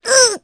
Rephy-Vox_Damage_jp_02.wav